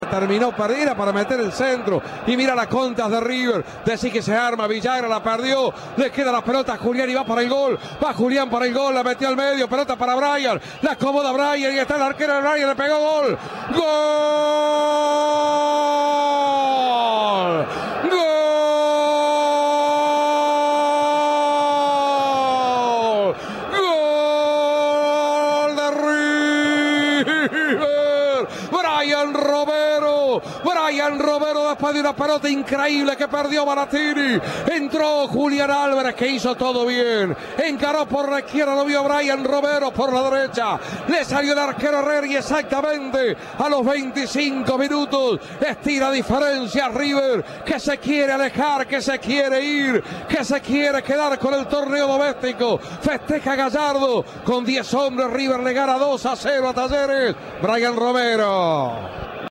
Reviví los relatos de los dos goles del equipo "millonario"